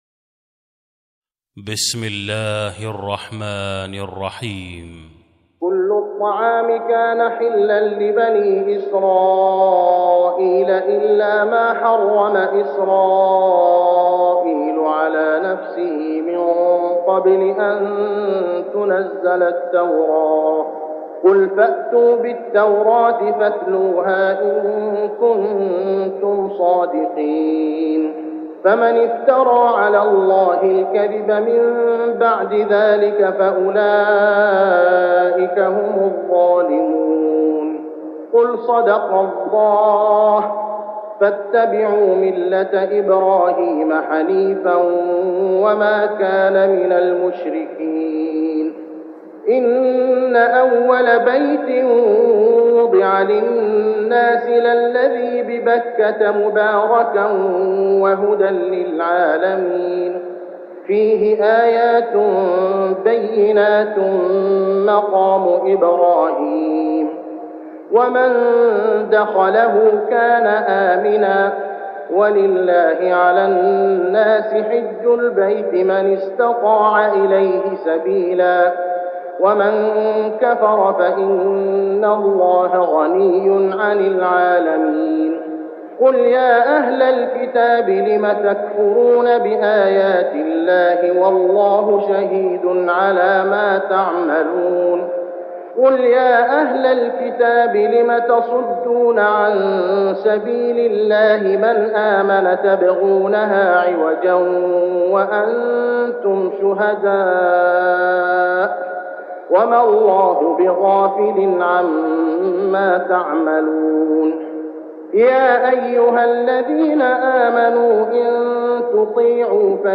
صلاة التراويح ليلة 5-9-1410هـ سورة آل عمران 93-165 | Tarawih prayer Surah Al-Imran > تراويح الحرم المكي عام 1410 🕋 > التراويح - تلاوات الحرمين